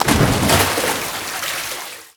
NPC_Leaving_Water.wav